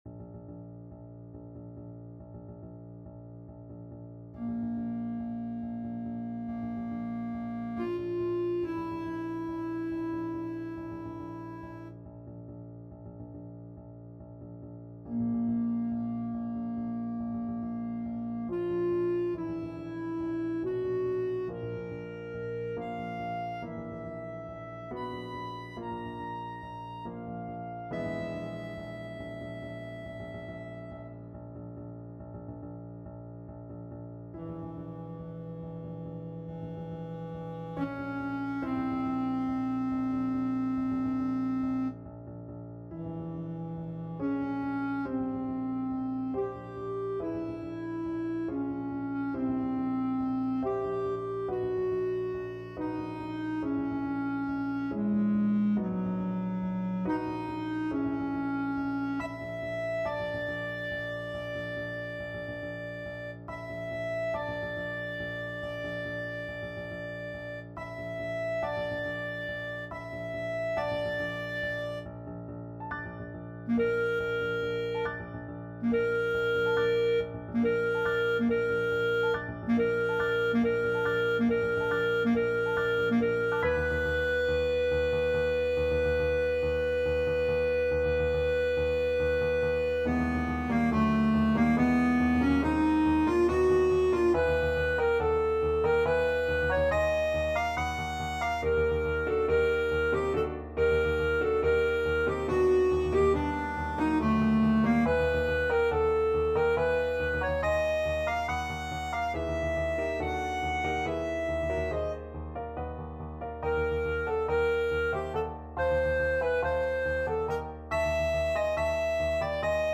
5/4 (View more 5/4 Music)
Allegro = 140 (View more music marked Allegro)
Classical (View more Classical Clarinet Music)